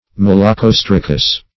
Malacostracous \Mal`a*cos"tra*cous\